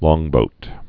(lôngbōt, lŏng-)